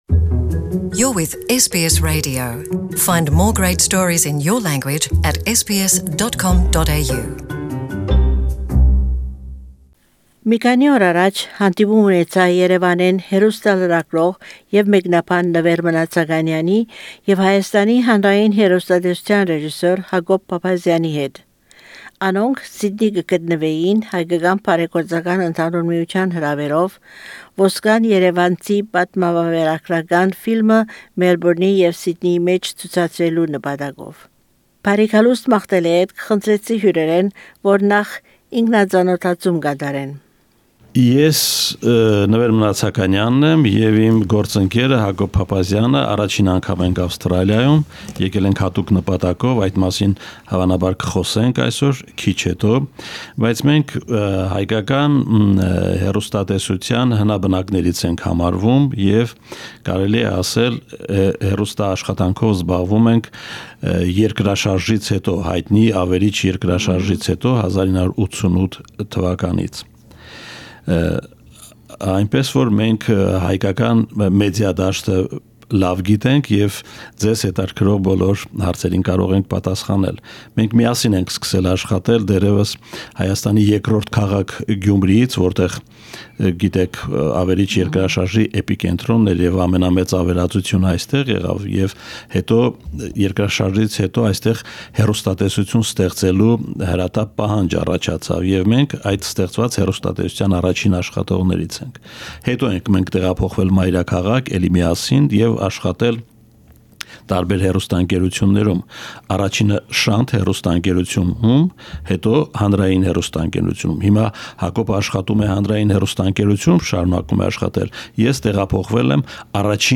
Interview with guests from Armenia